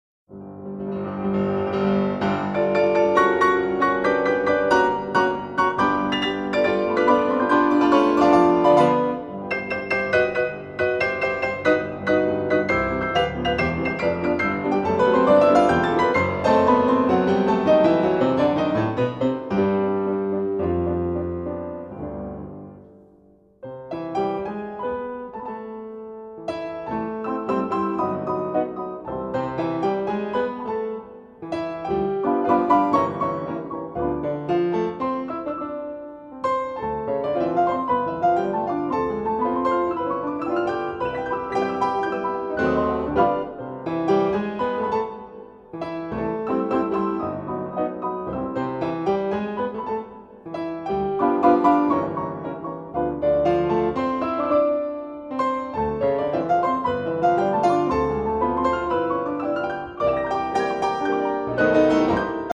Delightful Jazz Tunes for Two Pianos
The superb engineering also helps.